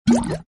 OctoshotBubbleShot.ogg